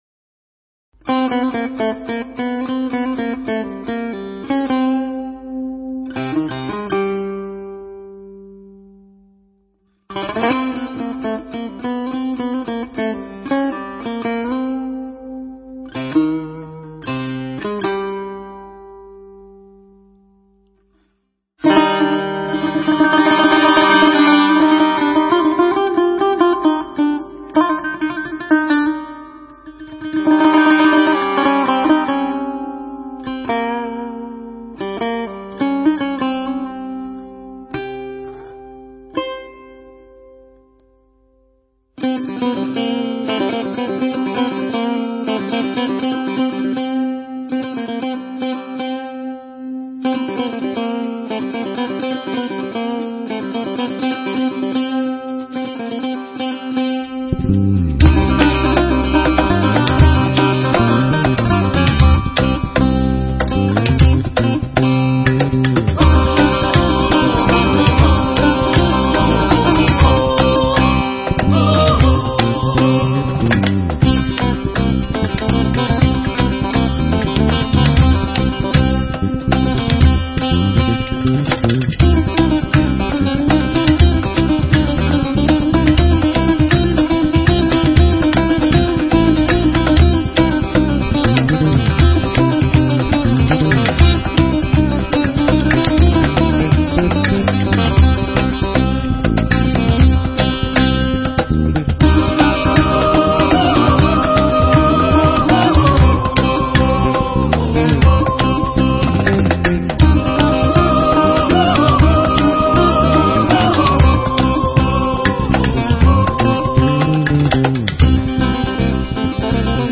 موسیقی ایرانی را در تلفیق علمی با موسیقی خاص غربی بنا نهاد